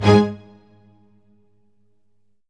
ORCHHIT G00L.wav